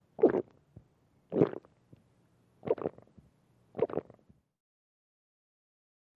Drinking with Loud Swallows & Bottle Glubs.